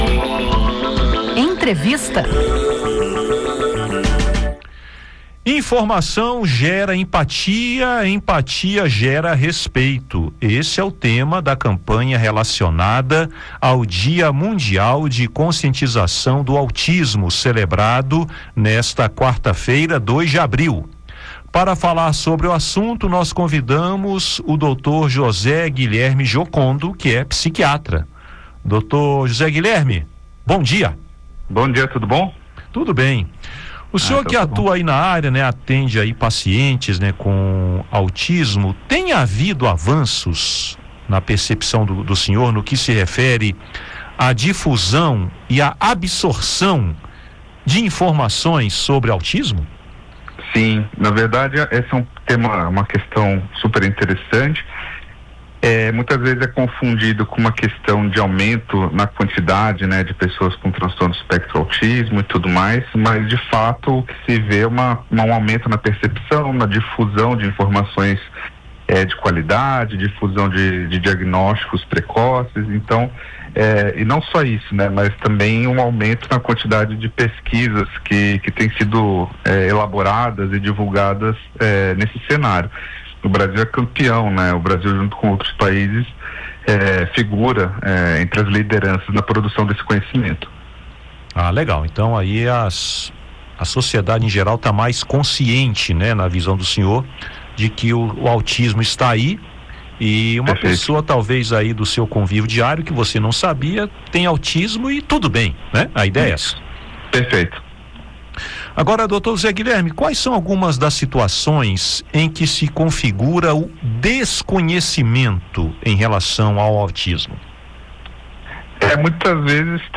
No Dia Mundial de Conscientização do Autismo, psiquiatra destaca desafios e avanços sobre o transtorno